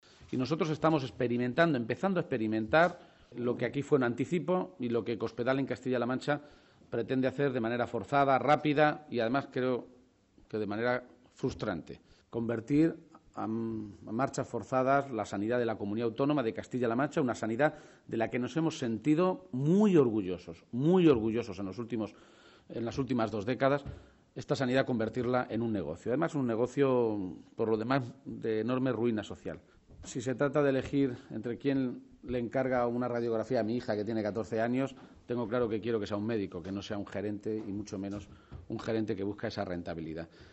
García-Page se pronunciaba de esta manera en una rueda de prensa conjunta, en Valencia, junto al secretario general del PSOE valenciano, en la capital de la comunidad vecina.
Cortes de audio de la rueda de prensa